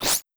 Slash3.wav